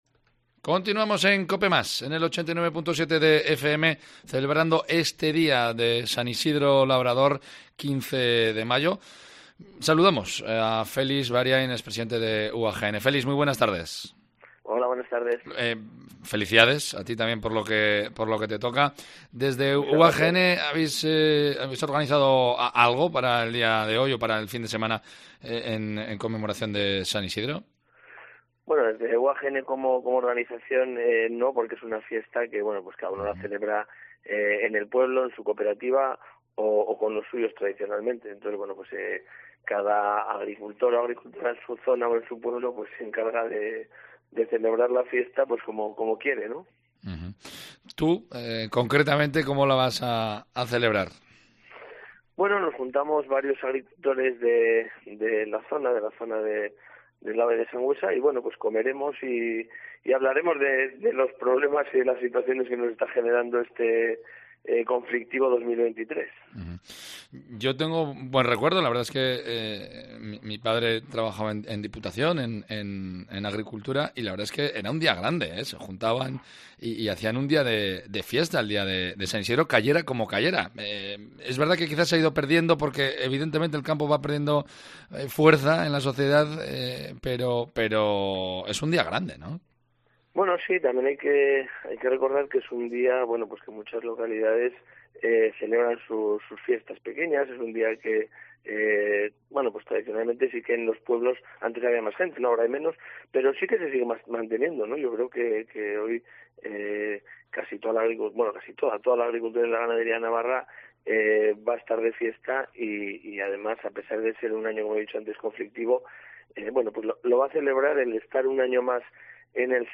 Hoy, en Cope Navarra, hablan cuatro hombres de campo de Arellano, Tudela, Cendea de Galar y Baztan sobre la situación del campo y cómo celebran esta festividad de San Isidro.